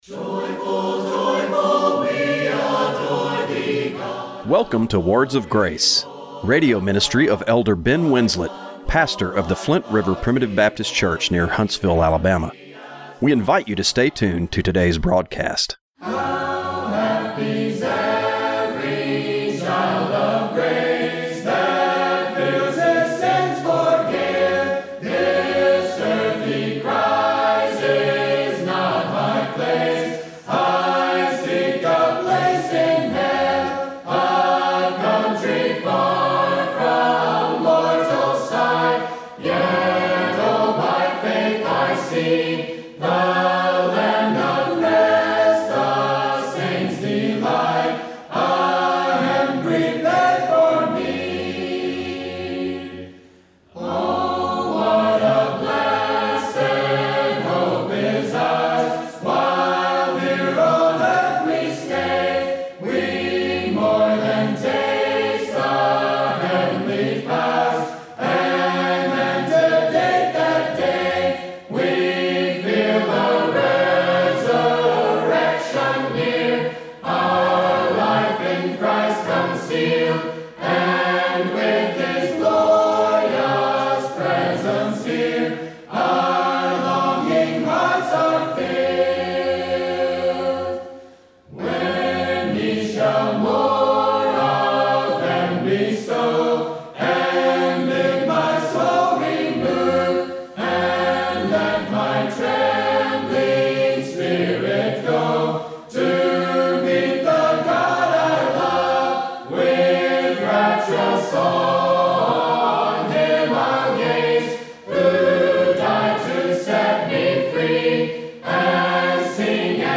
Radio broadcast for October 26, 2025.